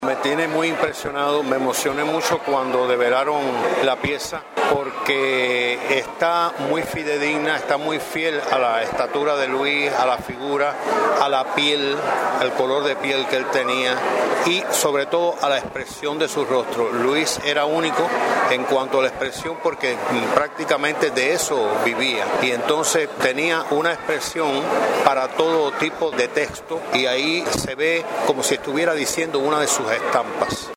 tuvo a su cargo las palabras centrales de la develación en las que acercó a los presentes a la obra del Acuarelista de la Poesía Antillana.